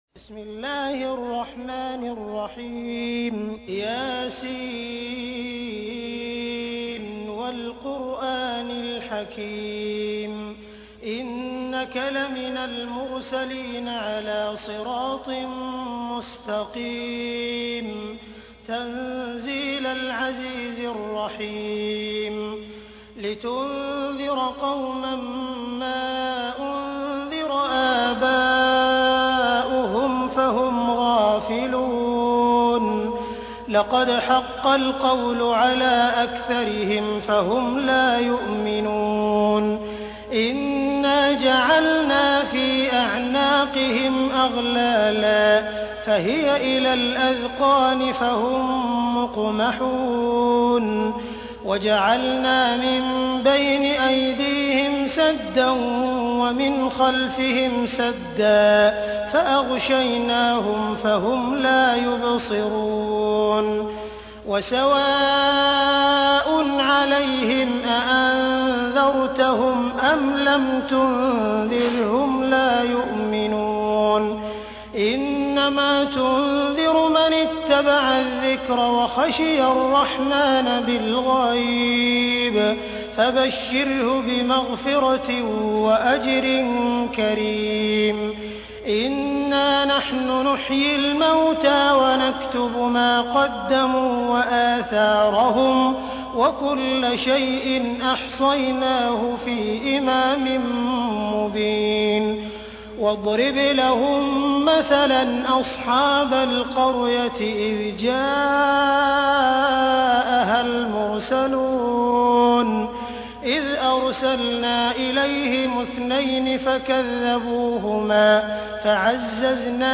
All tracks are MP3 format Recitation of the Noble Qur'aan By: Sheikh Abdur Rahman As-Sudais (IMAAM OF MAKKAH HARAM) Sheikh Abdur Rahman As-Sudais in born in 1961 at Riyadh, Saudi Arabia.
He is respected for his precise, controlled, and particularly emotional recitation of the Qur'an in accordance with Tajweed, the rules of beautiful recitation.